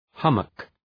{‘hʌmək}
hummock.mp3